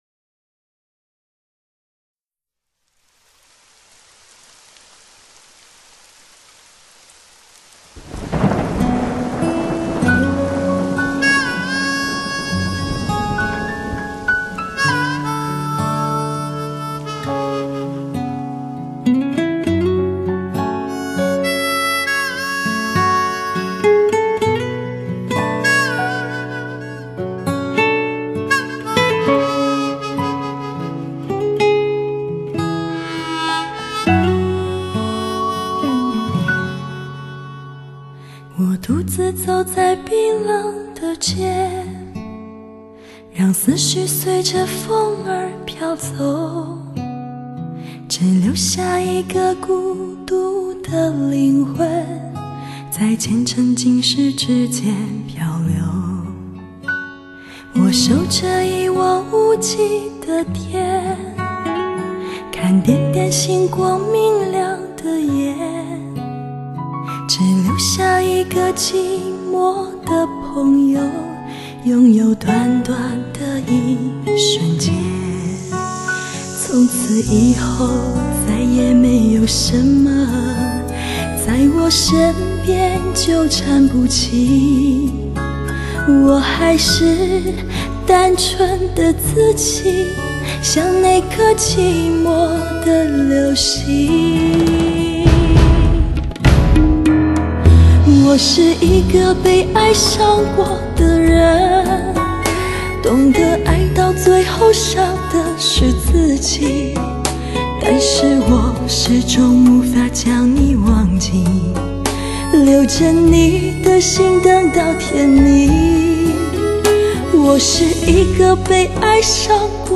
真正实现高清，全方位360环绕3D立体音效。